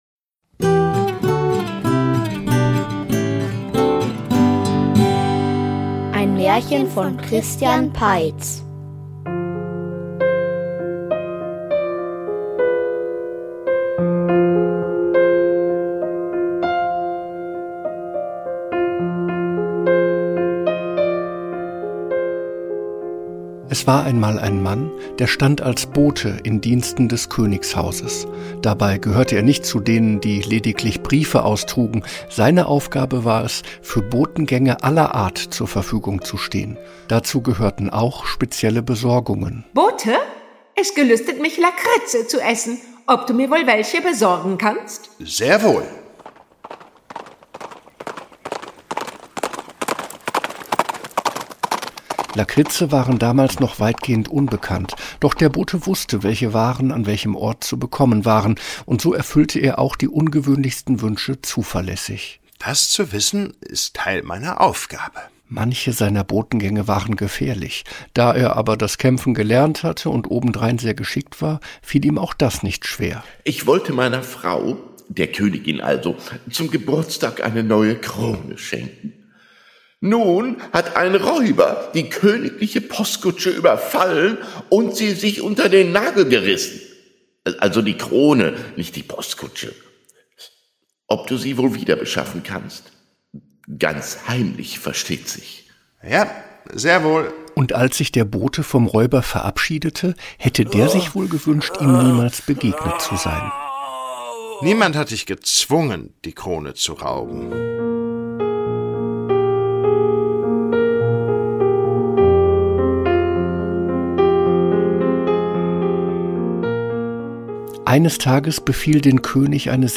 Der Oger --- Märchenhörspiel #64 ~ Märchen-Hörspiele Podcast